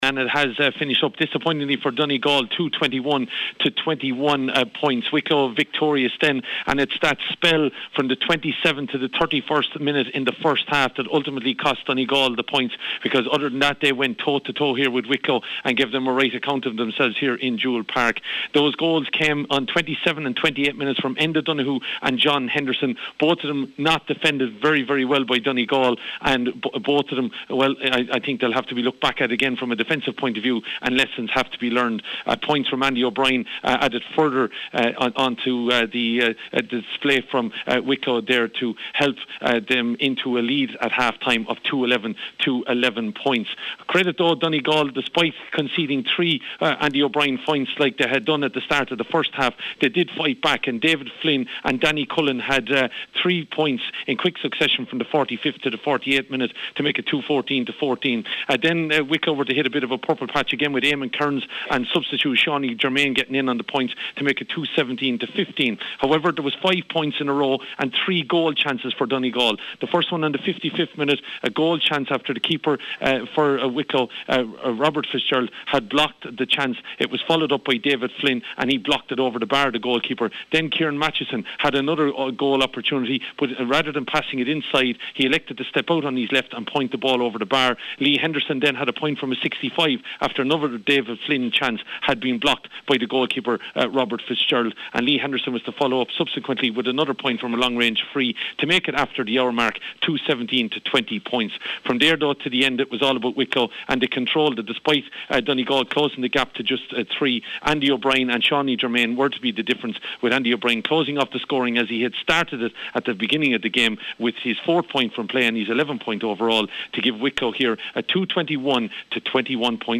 has the full-time report for Highland Radio Sport…